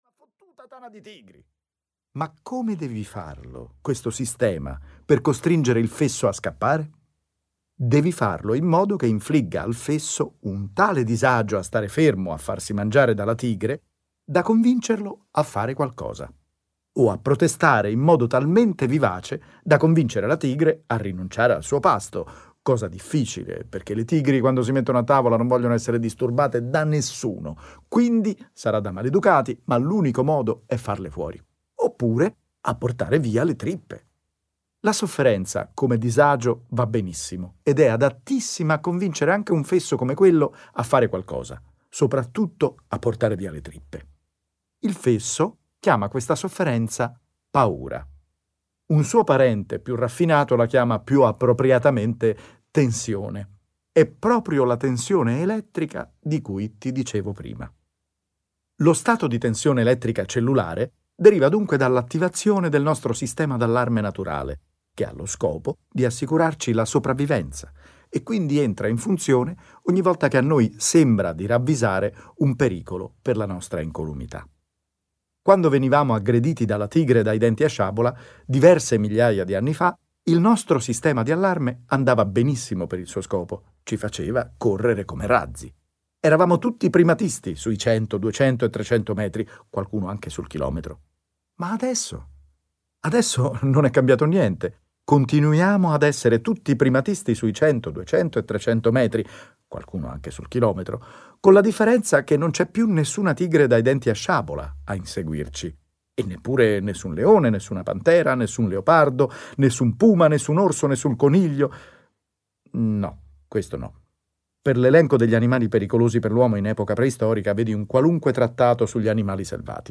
• Letto da: Marco Mete